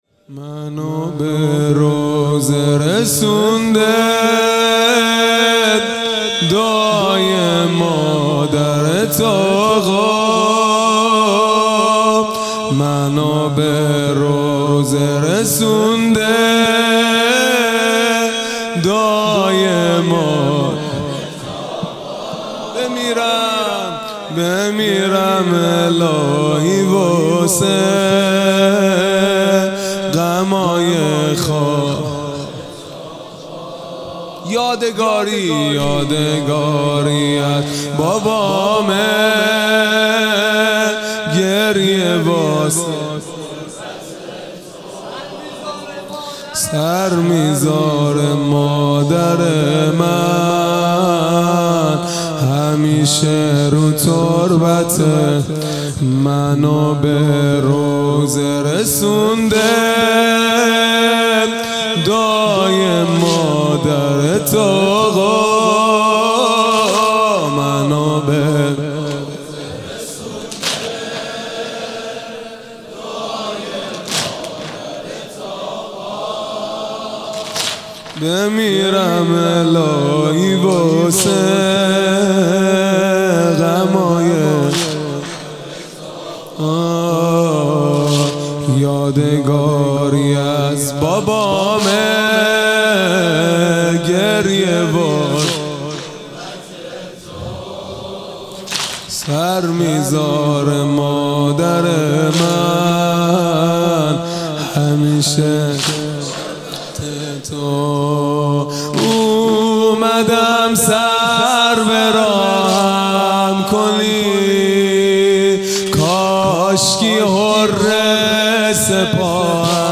خیمه گاه - هیئت بچه های فاطمه (س) - واحد | منو به روضه رسونده
محرم ۱۴۴۱ | شب هشتم